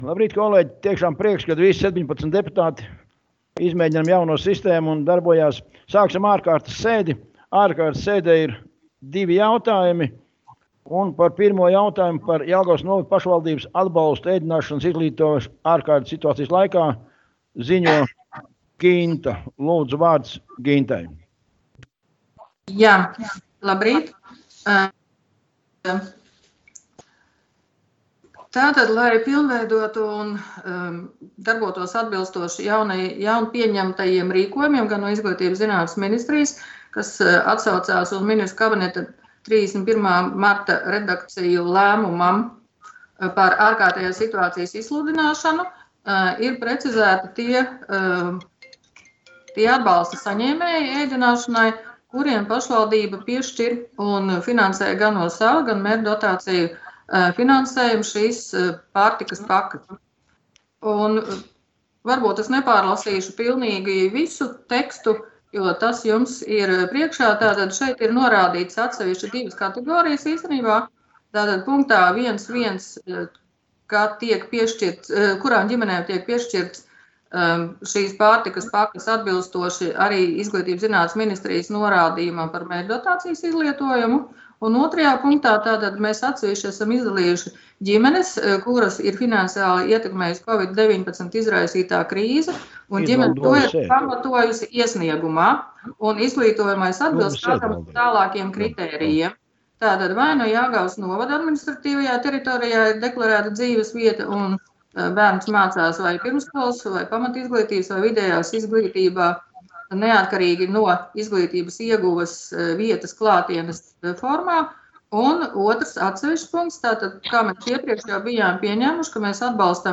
Domes ārkārtas sēde Nr. 8